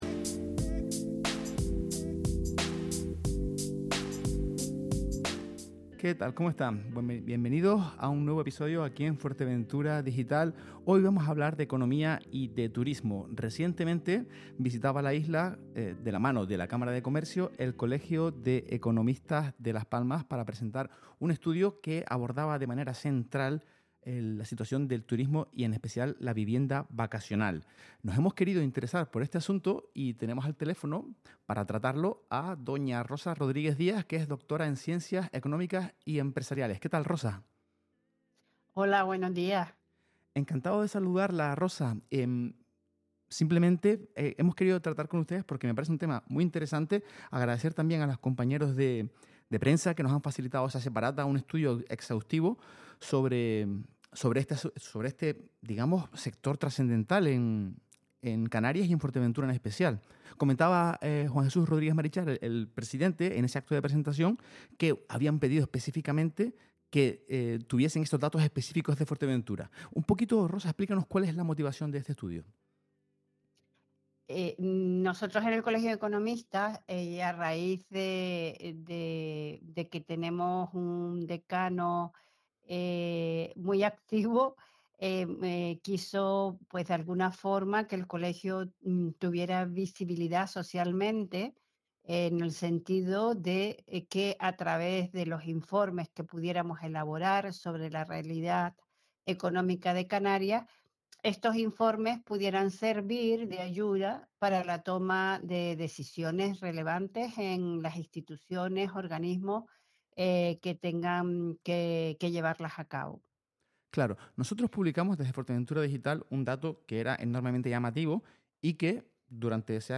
Nicasio Galván, portavoz de Vox en el Parlamento de Canarias y presidente del CEP de Las Palmas – Entrevistas y declaraciones en Fuerteventura Digital – Podcast